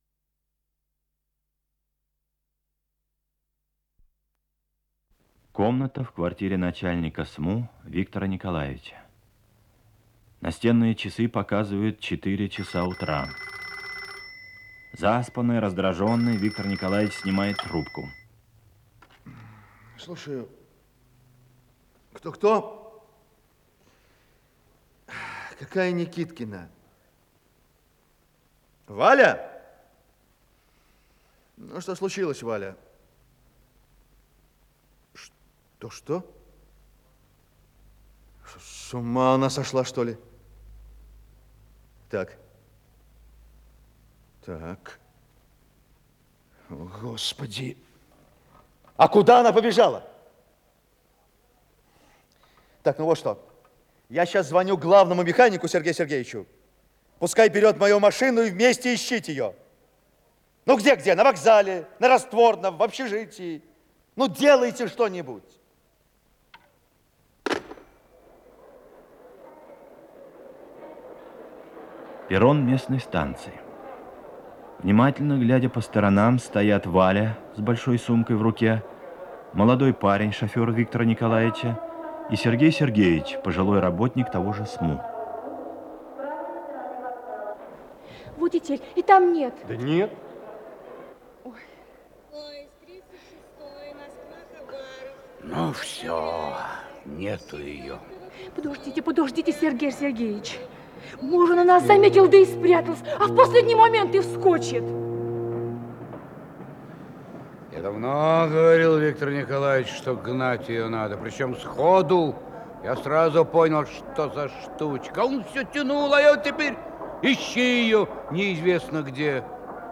Исполнитель: Артисты театра
Название передачи Зинуля Подзаголовок Спектакль Государственного академического Ярославского театра им. Ф. Волкова